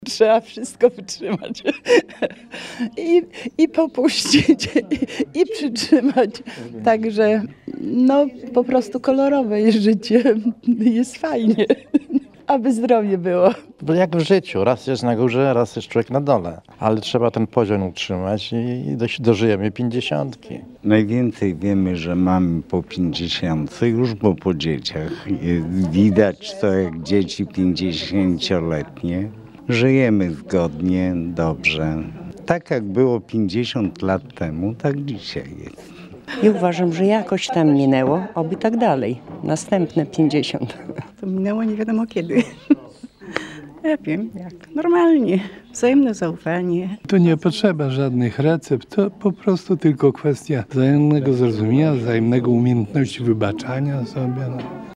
Siedem par odebrało w czwartek (26.07.18) w suwalskim ratuszu medale za długoletnie pożycie małżeńskie.
Jak mówiły odznaczone dziś pary, nie ma sprawdzonej recepty na udane małżeństwo. Najważniejsze jest wzajemne zrozumienie i umiejętność wybaczania.